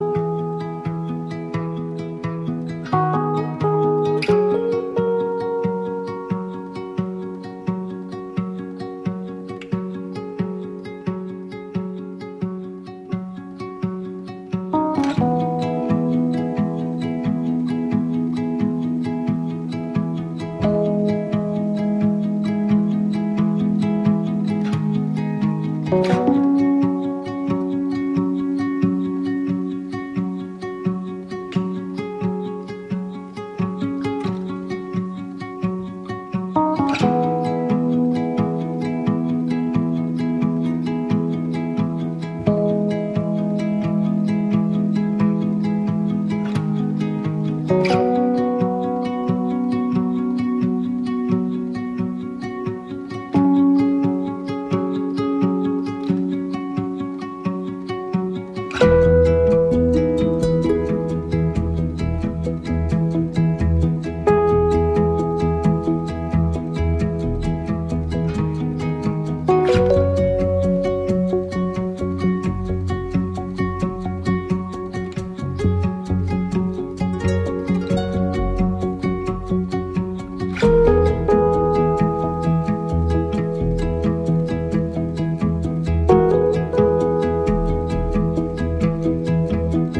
高揚するメロディーとフレーズの反復の源泉にはライヒの脈動も流れる、色彩豊かなニューエイジ・ジャズ傑作。